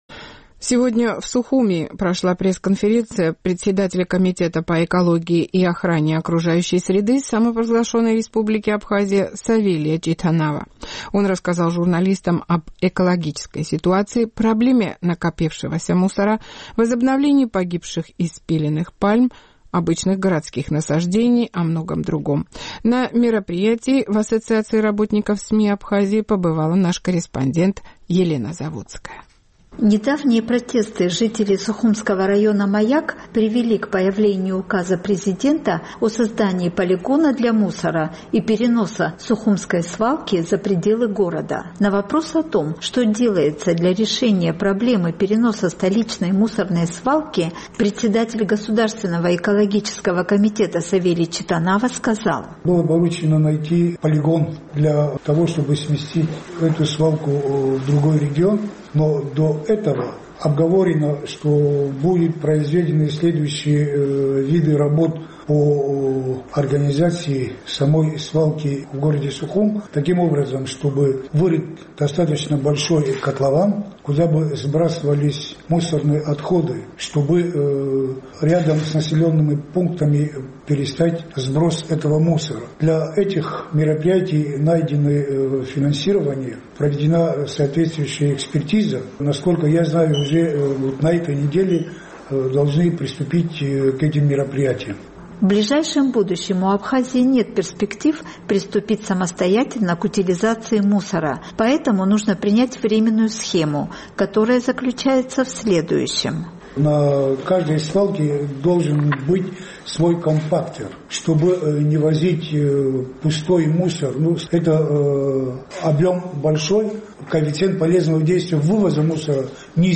Об экологической ситуации и многом другом шел разговор с председателем Государственного комитета по экологии и охране окружающей среды Савелием Читанава на пресс-конференции в Ассоциации работников СМИ Абхазии.